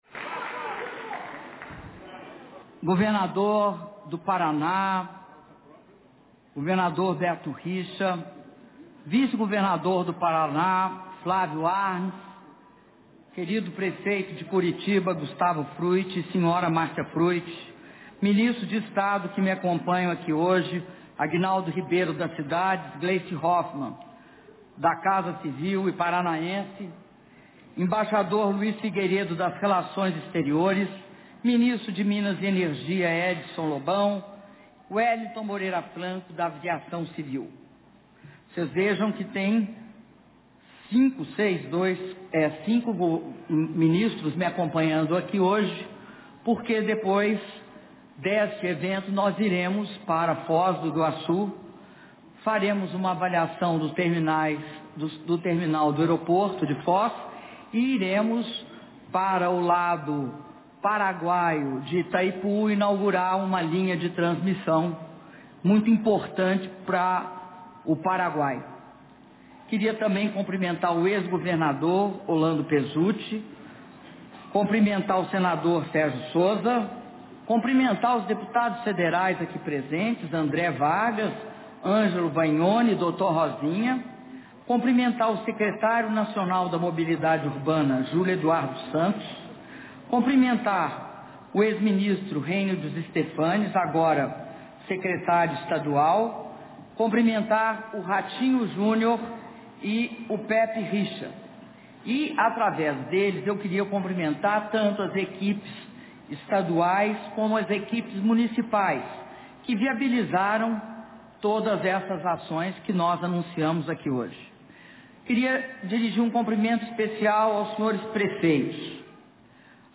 Áudio do discurso da Presidenta da República, Dilma Rousseff, na cerimônia de anúncio de investimentos do PAC Mobilidade Urbana - Curitiba/PR